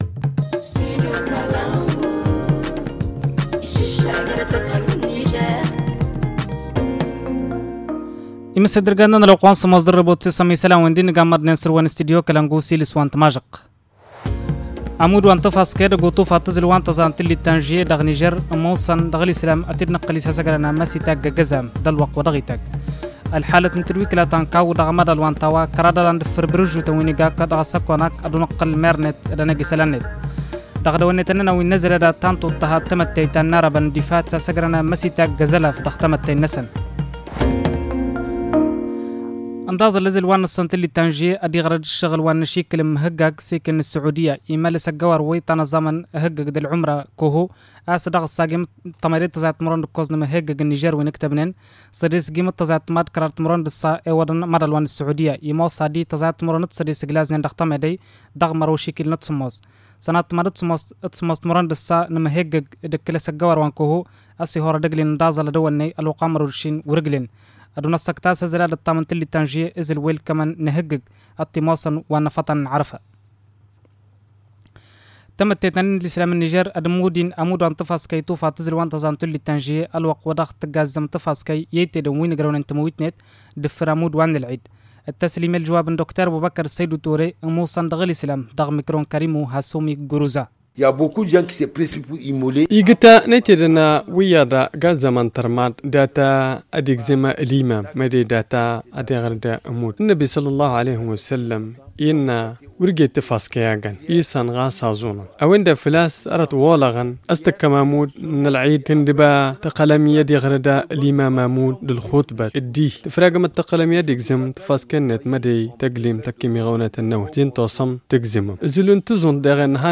Le journal du 8 juillet 2022 - Studio Kalangou - Au rythme du Niger